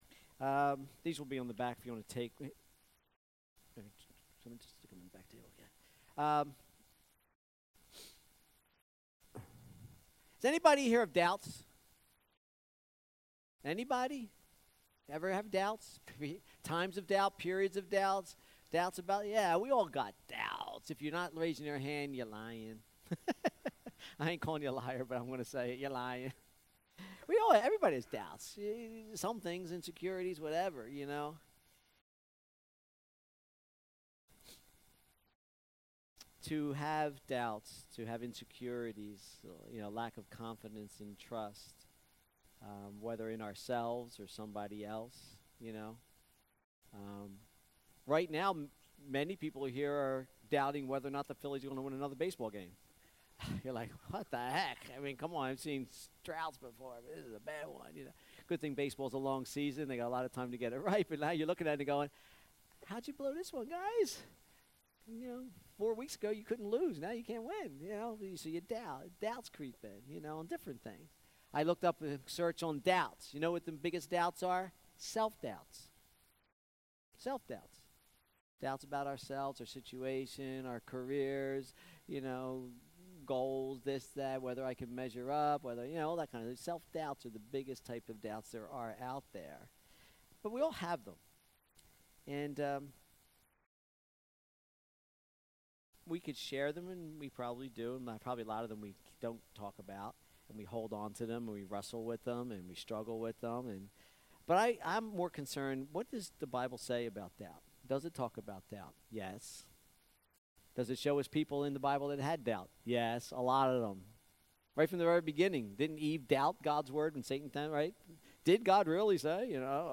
Sermons | First Christian Assembly